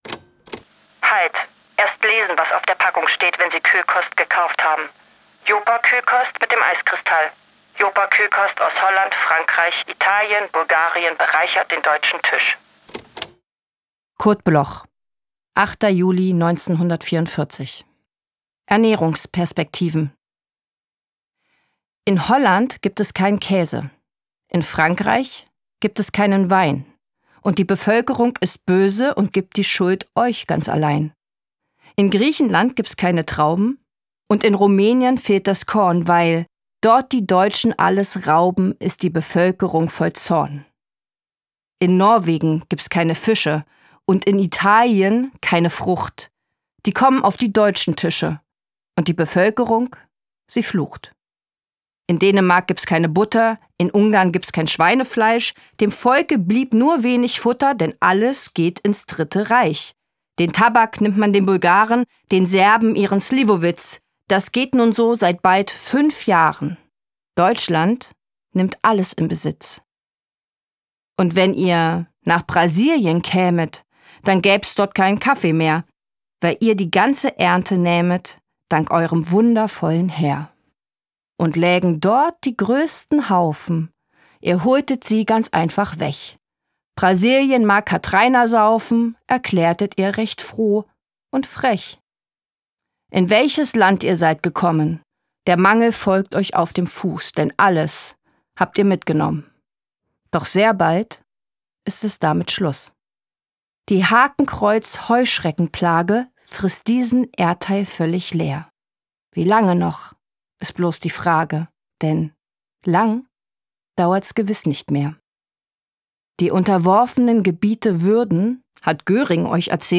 voorgedragen door Luise Heyer